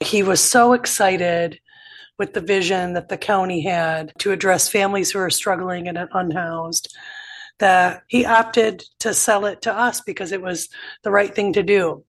County board chair Jen Strebs, who was there to sign the papers, said she was impressed by the owner selling the property because he had had the opportunity to sell it for more.